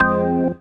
ORGAN-20.wav